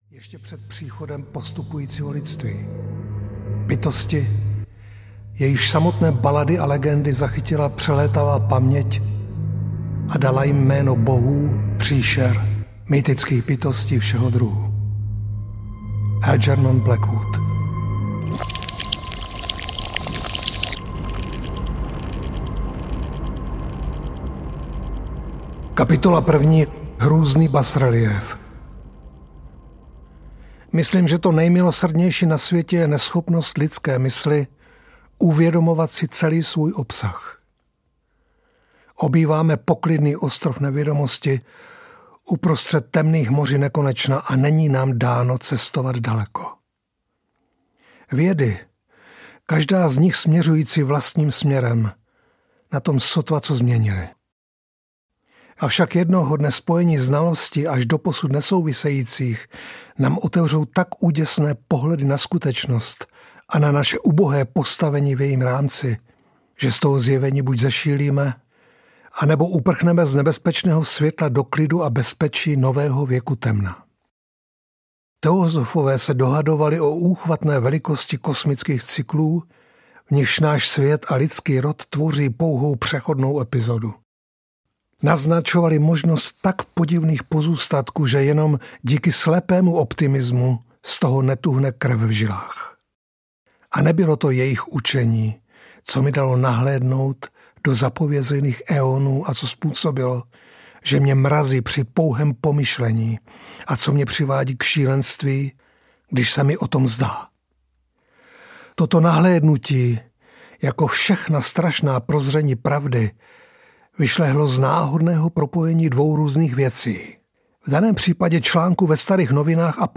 Ukázka z knihy
• InterpretMiroslav Krobot